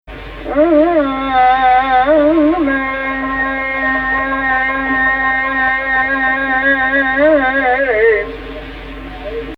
Her very young performance here of a Rast Layali (a vocal improvisation on the syllables "Ya Layl") is more or less a condensed version of the Layali Abul-3ala performs: it follows the identical pathways through vocal areas (ajnas), and even some of the same melodies, in about half the time.
Actual Key: Rast on C